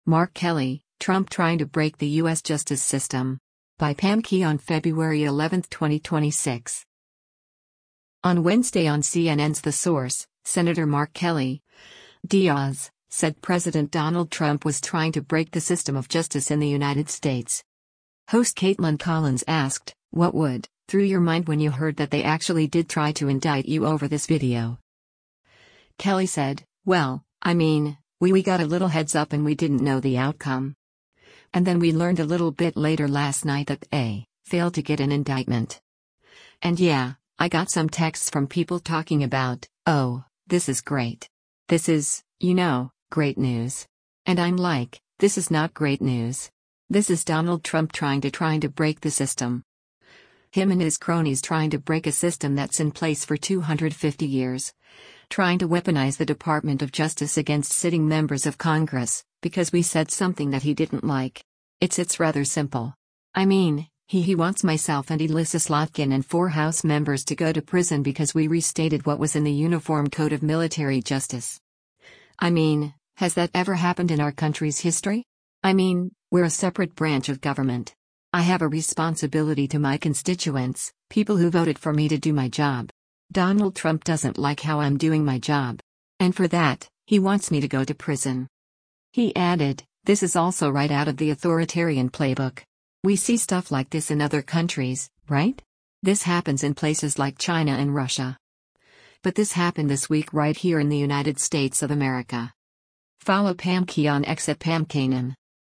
On Wednesday on CNN’s “The Source,” Sen. Mark Kelly (D-AZ) said President Donald Trump was trying to break the system of justice in the United States.
Host Kaitlan Collins asked, “What would, through your mind when you heard that they actually did try to indict you over this video?”